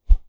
Close Combat Swing Sound 4.wav